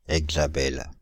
Ääntäminen
France (Île-de-France): IPA: /ɛɡ.za.bɛl/